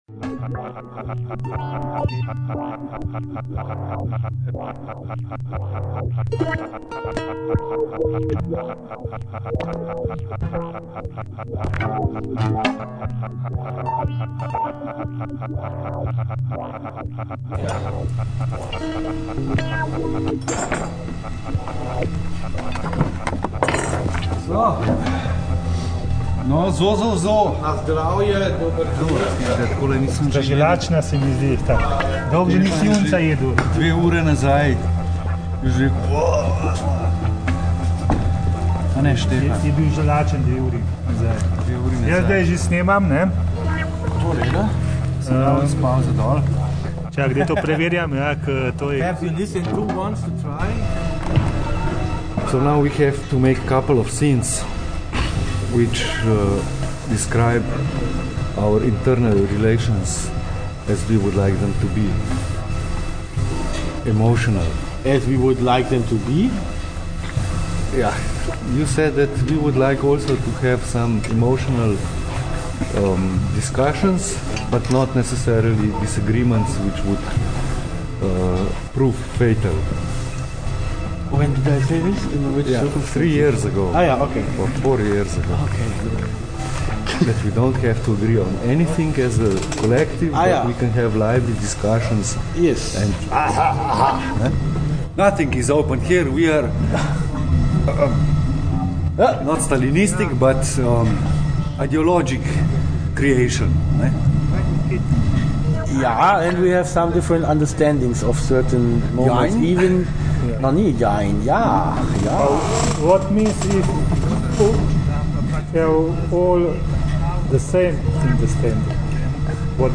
In the first instalment we prepared a radio show discussion hopefully touching some of the important conceptual approaches regarding the public appearances of Cirkulacija 2. While more abstractly coded radio broadcast could be used, we decided for the more basic approach – the discussion, the talk.
The language is the CE broken english style – as one would expect it from an international group of artists.